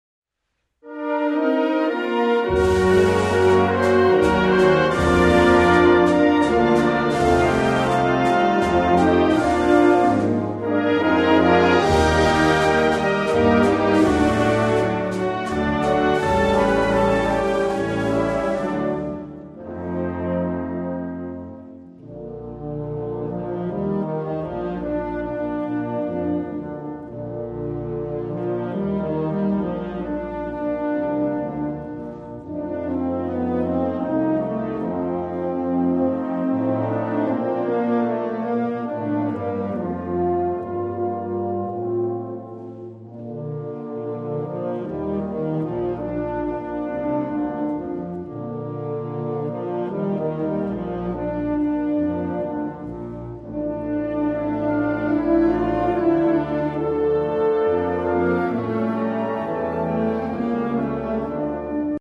Moeilijkheidsgraad B - eenvoudig